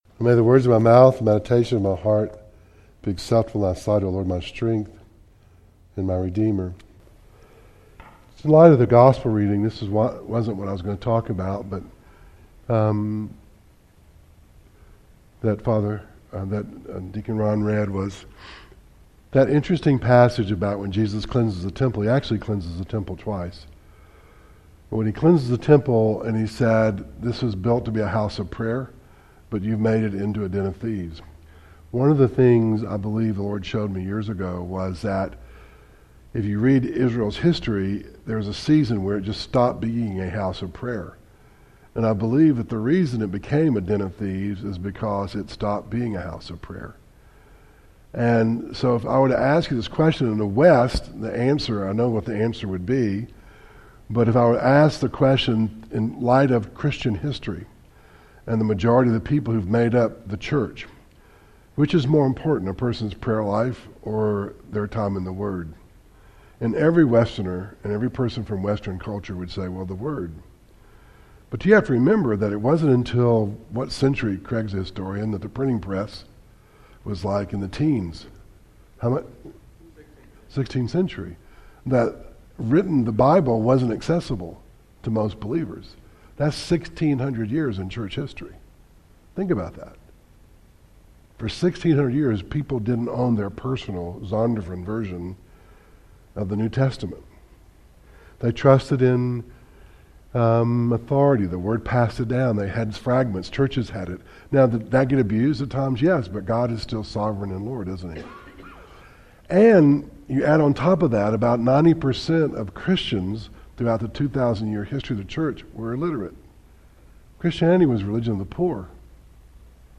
Acts 27 Service Type: Wednesday Night